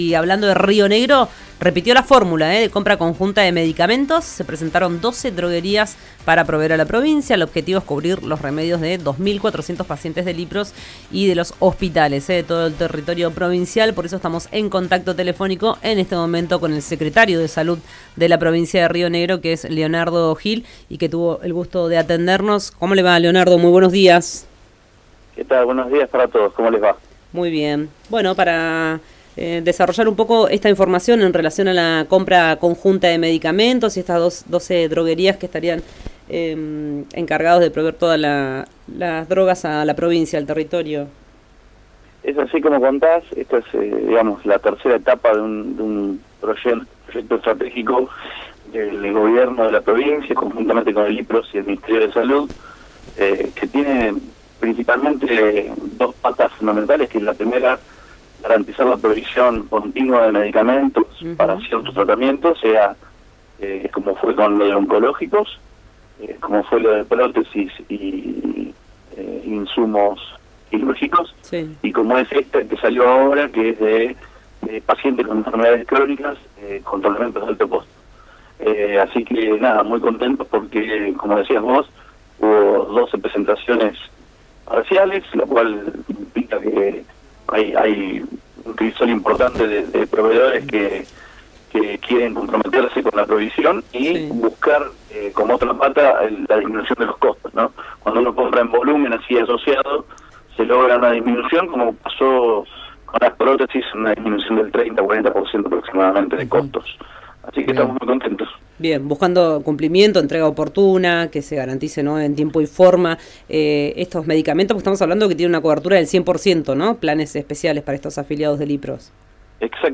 Escuchá a Leonardo Gil, secretario del Ministerio de Salud de la provincia de Río Negro en RÍO NEGRO RADIO: